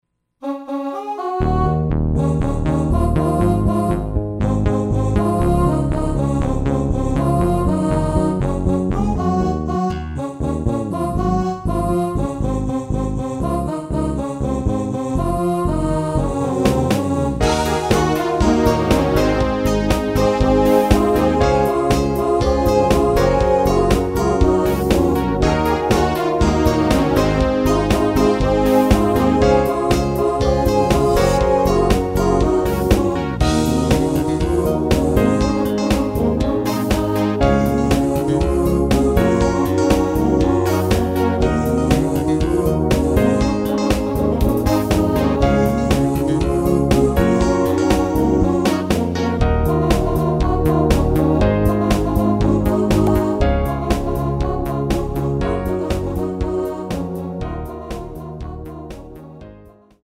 Canal 01: Electric Bass (finger) – 413 notas
Canal 03: Electric Guitar (clean) – 839 notas
Canal 04: Pad 2 (Warm) – 325 notas
Canal 08: Voice Oohs – 403 notas
Canal 09: Choir Aahs – 462 notas
Canal 10: Drums – 597 notas
Canal 11: Electric Grand Piano – 248 notas
Canal 14: Lead 2 (Sawtooth) – 140 notas
Canal 15: Synth Bass 1 – 213 notas